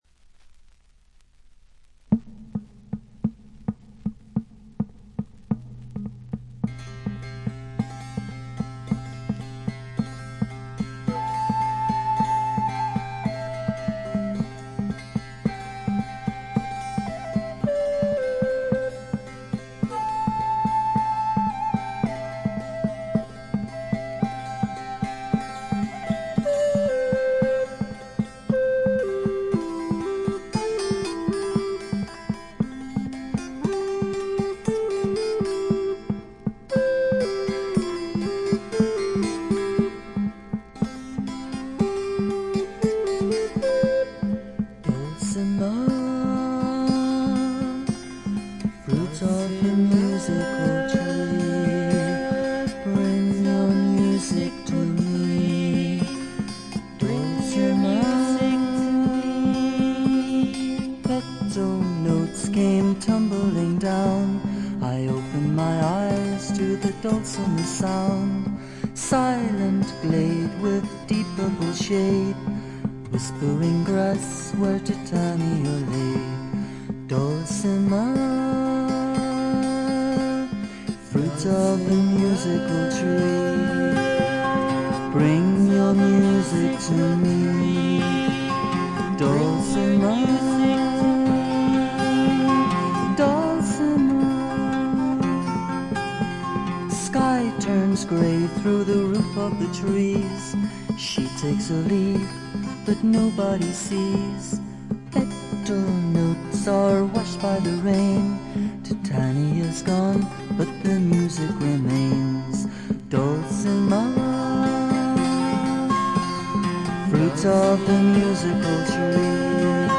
軽微なプツ音少々、静音部でわずかなチリプチ。
ギター、マンドリン、ダルシマー等のアコースティック楽器のみによるフォーク作品。
試聴曲は現品からの取り込み音源です。